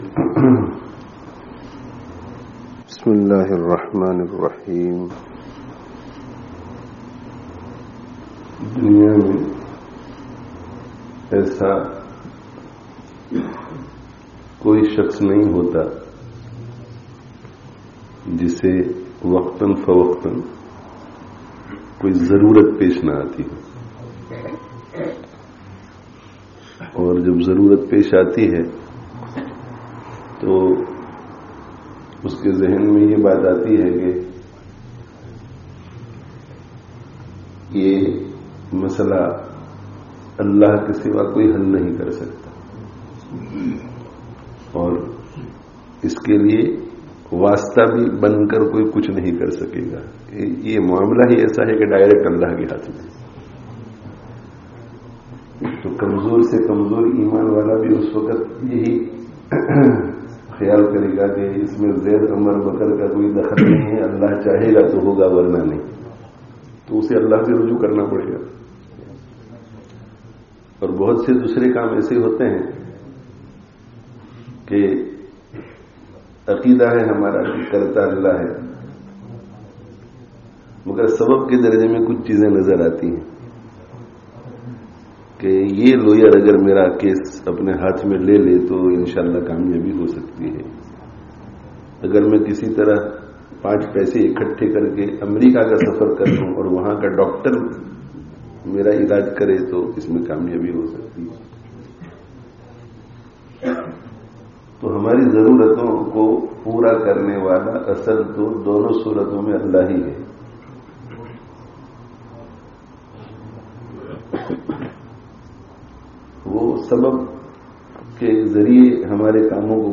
[Informal Majlis] Du'a (05/10/19)